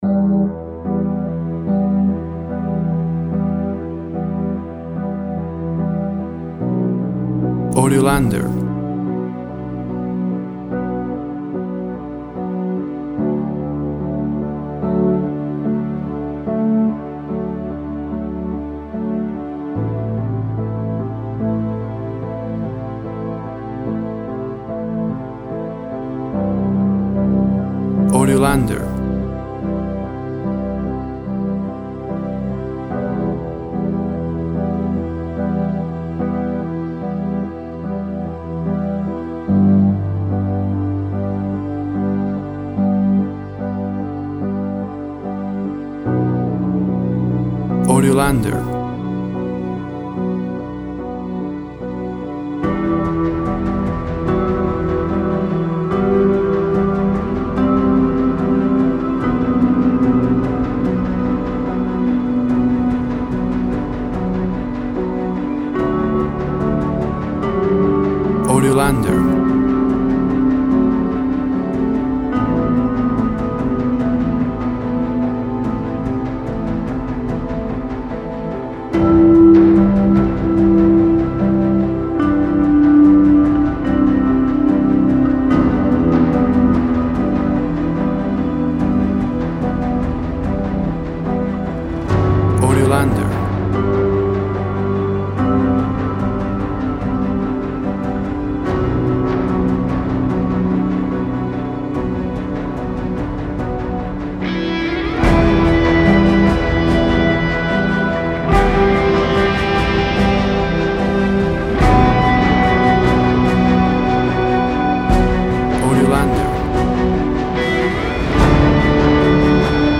Epic and emotional sci-fi instrumental.
Tempo (BPM) 72